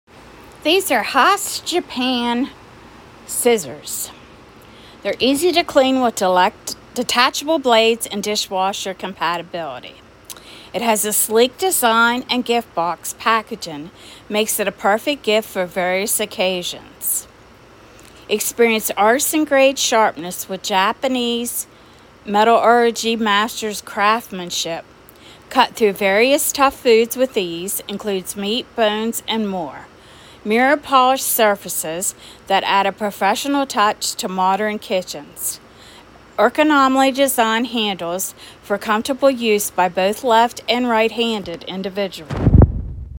Huusk kitchen scissors sound effects free download